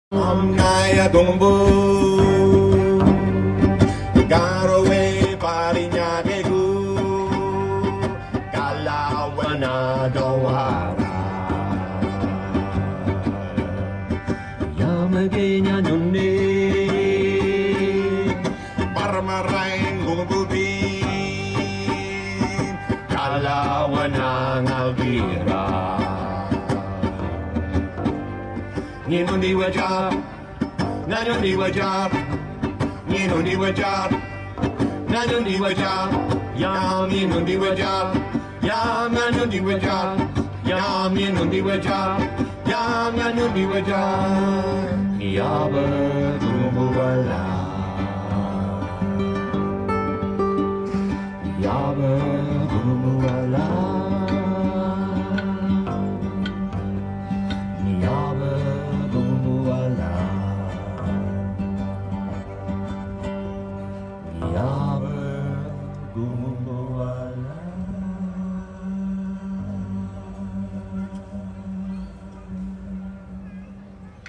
a giant is walking around the festive space.